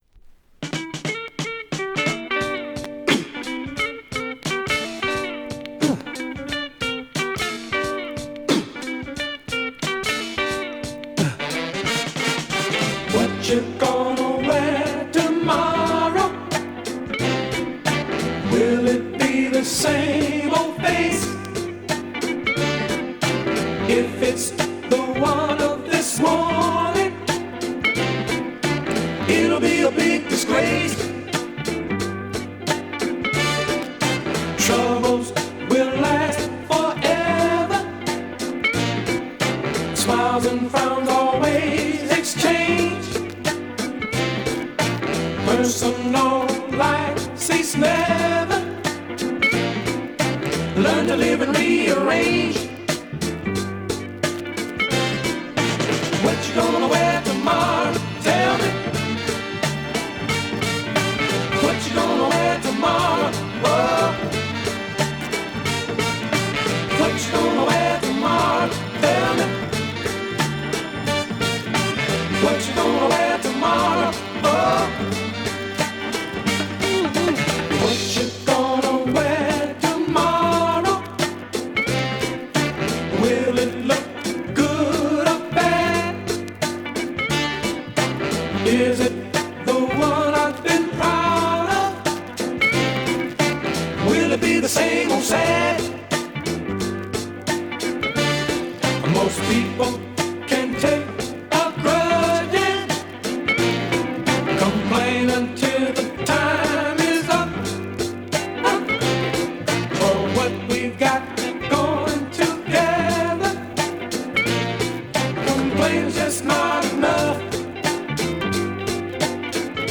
BPM 77 -> 88 にテンポアップ